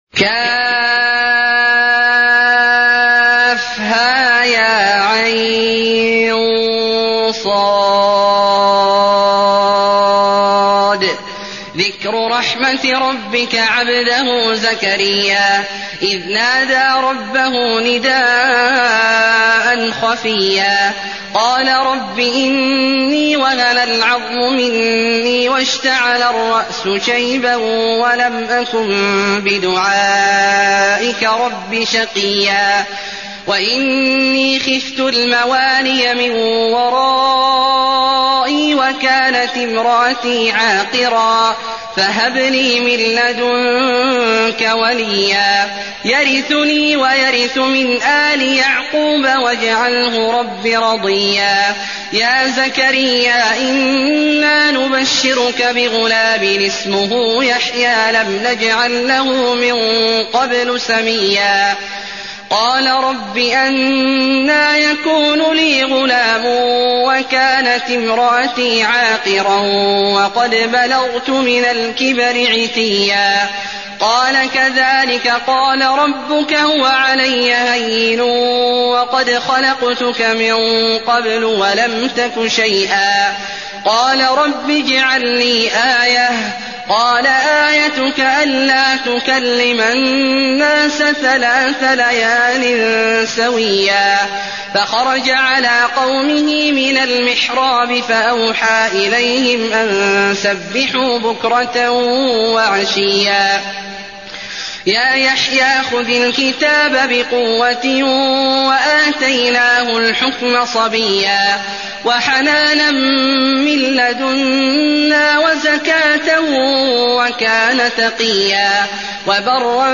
المكان: المسجد النبوي مريم